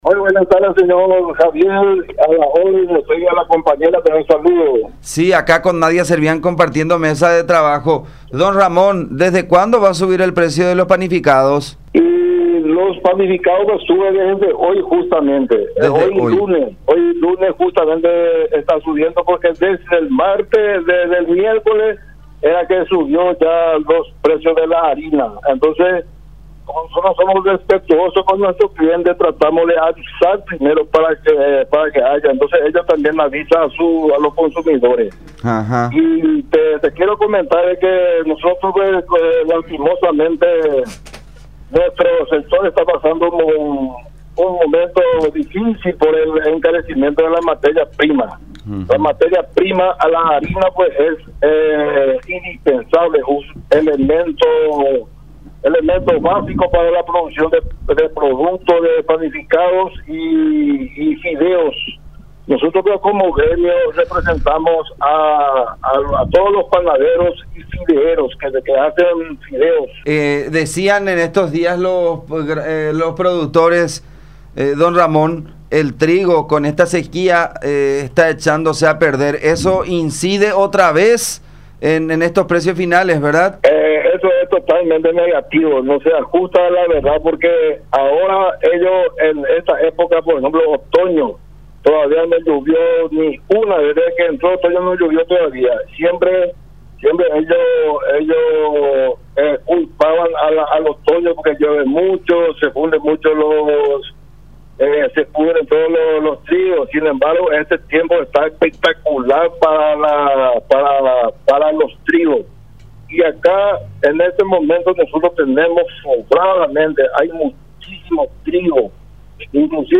“Estamos pasando un momento difícil por el encarecimiento de la materia prima”, dijo el entrevistado.